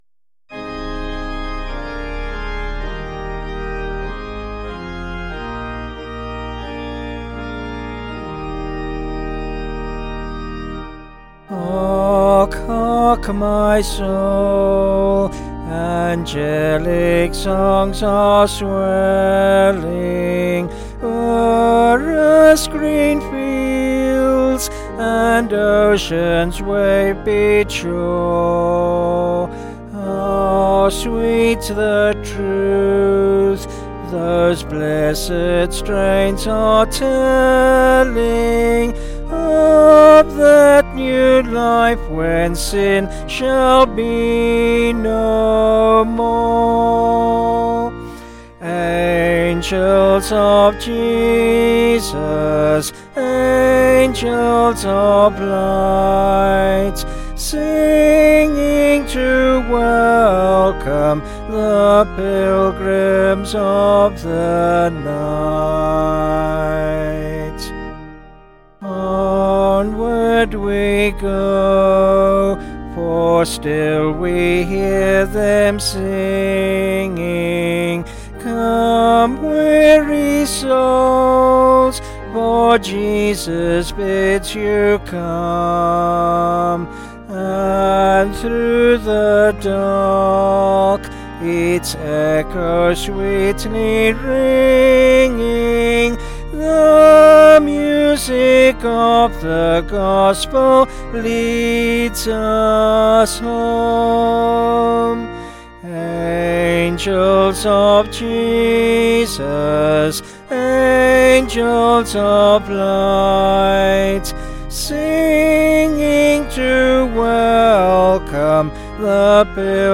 Vocals and Organ